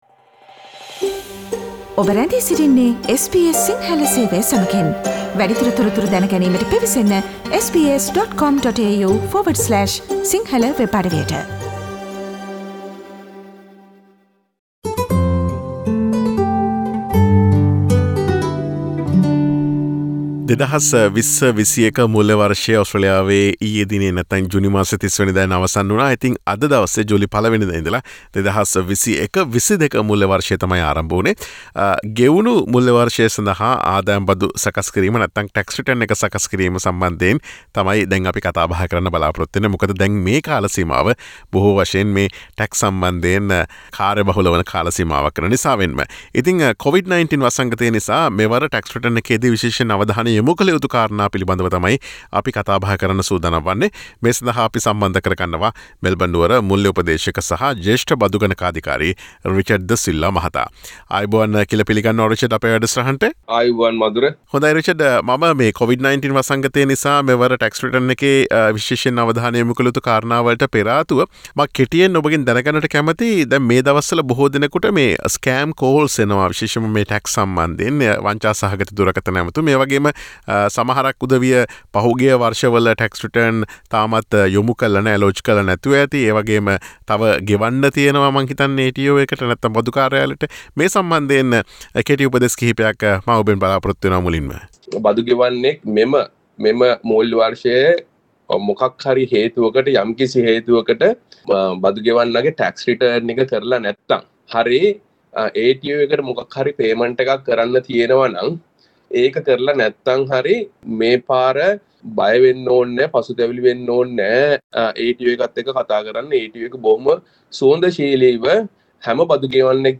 Covid-19 වසංගතය නිසා මෙවර Tax Return එකේදි විශේෂයෙන් අවධානය යොමු කළ යුතු අංශ මොනවාද සහ මෙවර Tax Return එක යොමු කළ යුතු අවසන් දින වකවානු මොනවාද පිළිබඳව SBS සිංහල ගුවන් විදුලිය සිදුකළ සාකච්ඡාවට සවන්දෙමු.